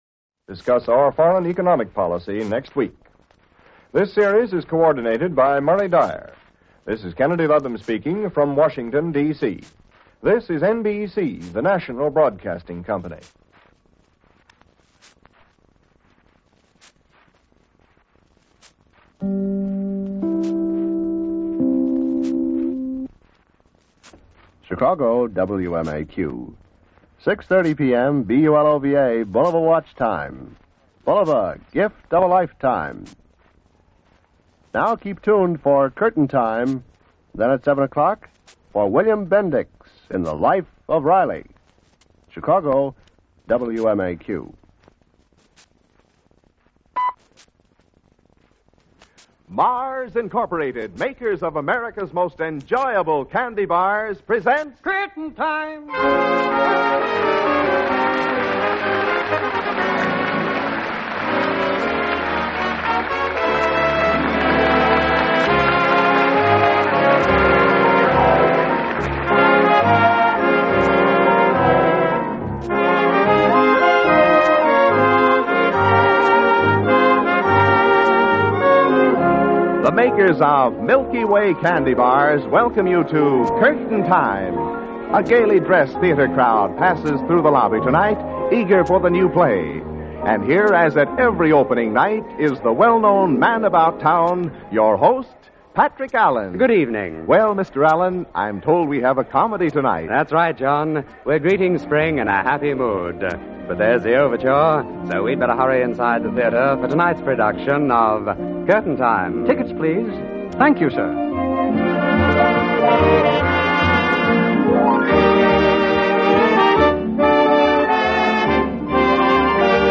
Curtain Time was a popular American radio anthology program that aired during the Golden Age of Radio.
Format and Features: "Theater Atmosphere": The show used sound effects and announcements to evoke the feeling of being in a theater, with an announcer acting as an usher and reminding listeners to have their tickets ready. Romantic Dramas: Each episode featured a different romantic story, often with a "boy meets girl" theme.